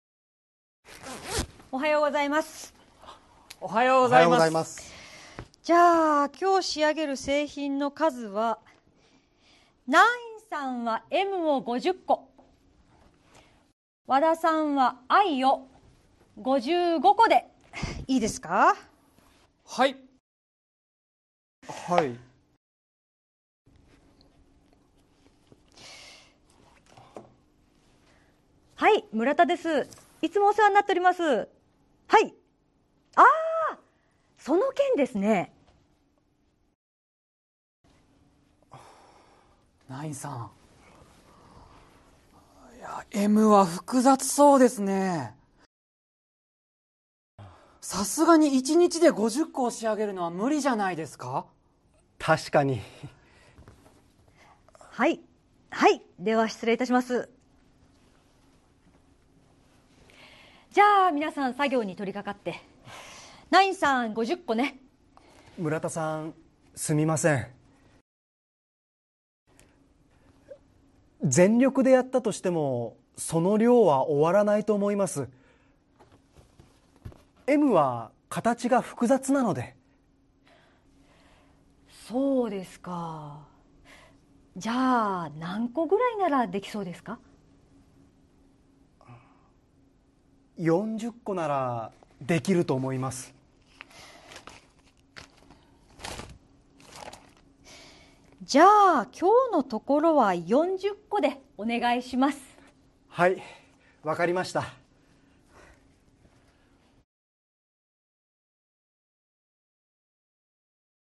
Role-play Setup
Conversation Transcript